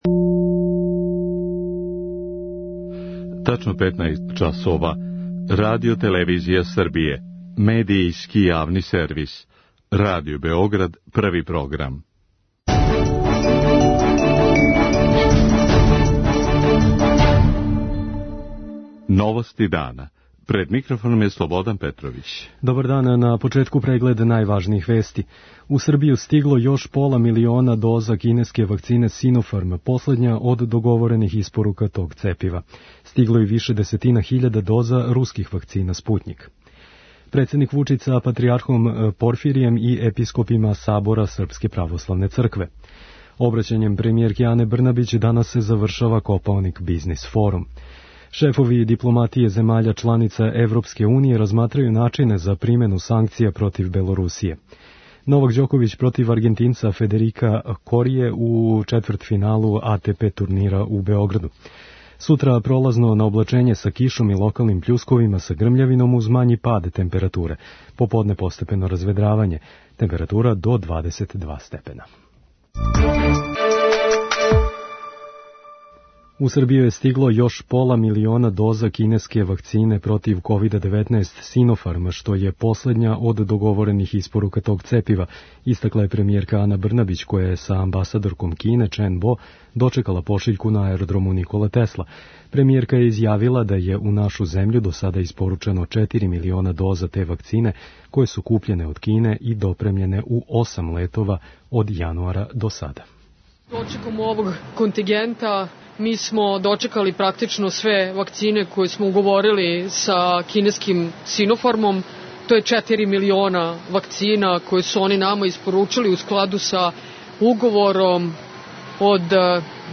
Саопштила је и да је у Србији до сада вакцинисано 45,3 одсто пунолетног становништва. преузми : 6.29 MB Новости дана Autor: Радио Београд 1 “Новости дана”, централна информативна емисија Првог програма Радио Београда емитује се од јесени 1958. године.